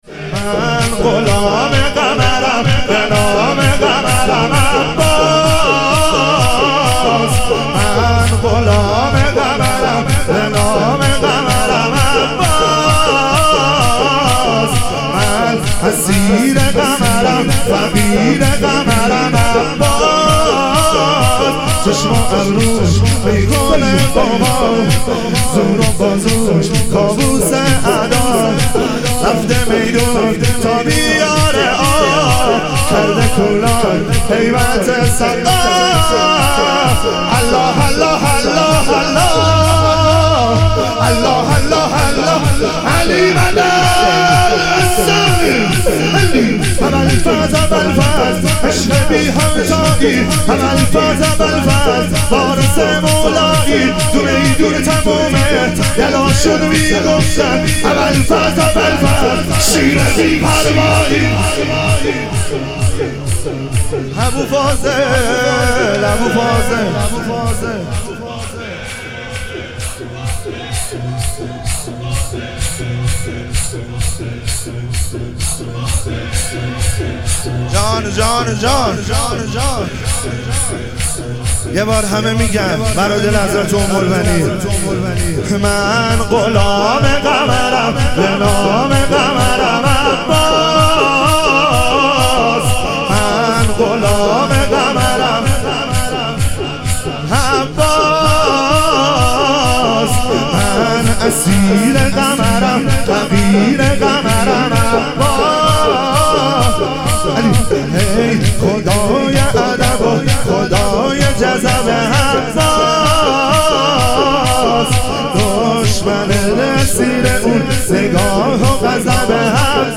اربعین امام حسین علیه السلام - شور